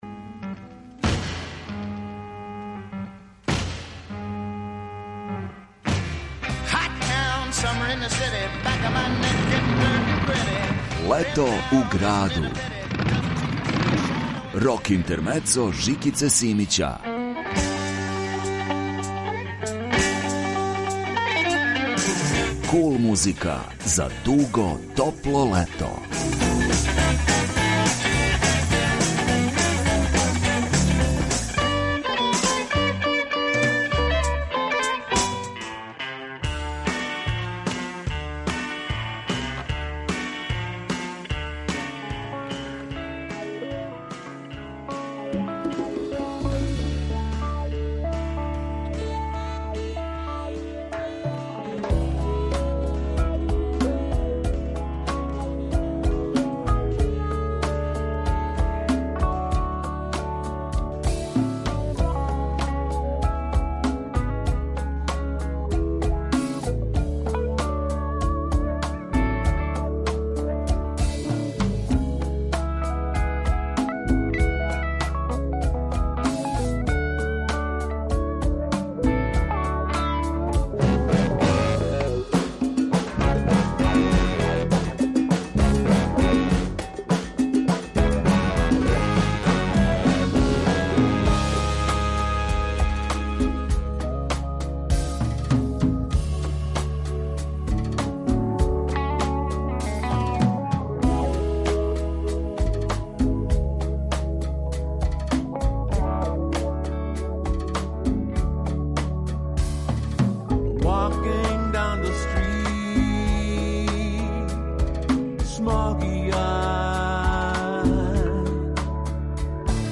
Kул музика за дуго топло лето.